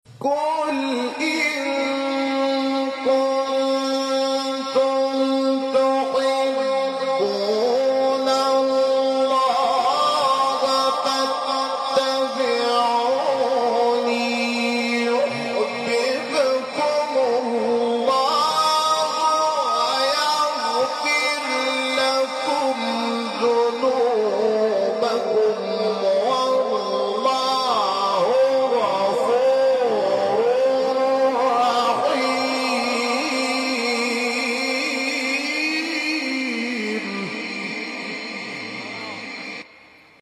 تلاوت 31 آل عمران محمود شحات دلنشین | نغمات قرآن
سوره:‌ آل عمران آیه:‌31 استاد:‌ محمود شحات مقام: بیات قُلْ إِن كُنتُمْ تُحِبُّونَ اللَّهَ فَاتَّبِعُونِي يُحْبِبْكُمُ اللَّهُ وَيَغْفِرْ لَكُمْ ذُنُوبَكُمْ ۗ وَاللَّهُ غَفُورٌ رَّحِيمٌ قبلی